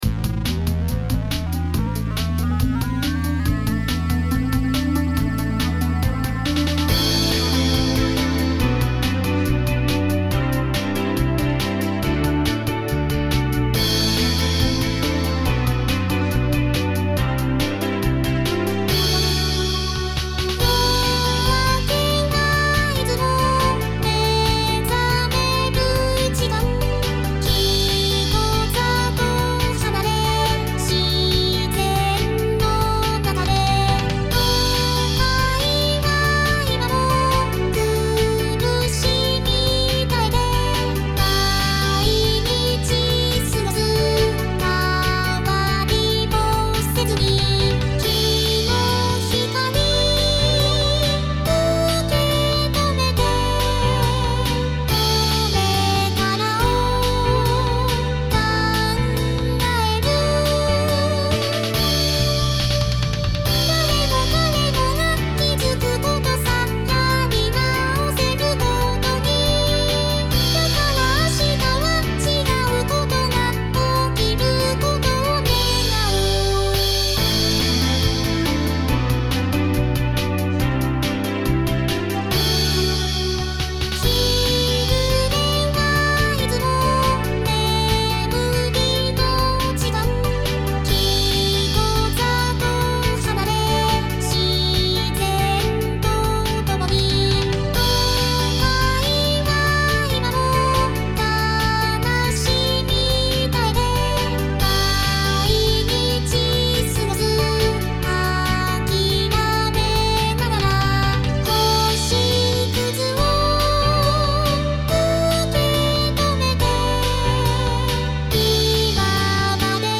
VSTi